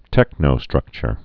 (tĕknō-strŭkchər)